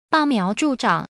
Bá miáo zhù zhǎng